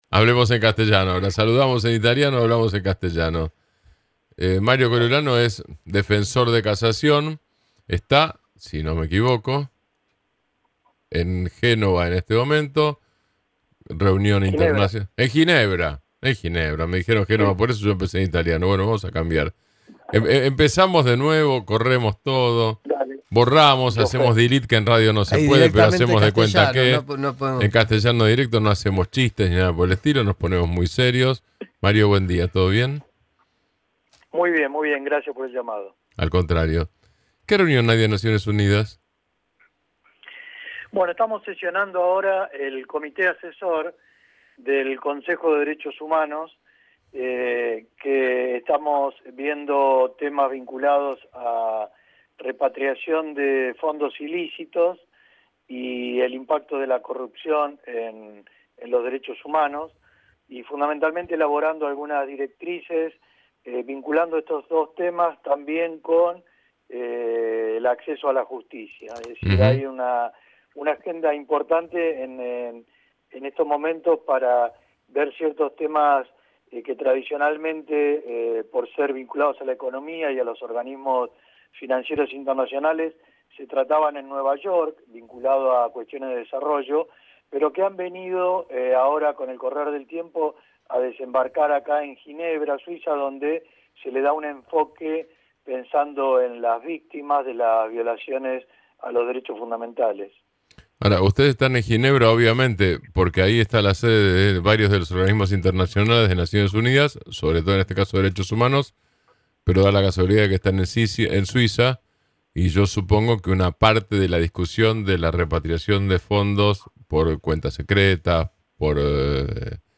El Defensor de Casación del Tribunal Penal bonaerense, Mario Coriolano, además miembro del Comité Asesor del Consejo de Derechos Humanos de las Organización de las Naciones Unidas, se refirió hoy a los mecanismos que estudia la ONU con vistas a que los países en vías de desarrollo puedan repatriar los millonarios fondos ilícitos que son escondidos en los paraísos fiscales producto de la corrupción y otras maniobras ilegales.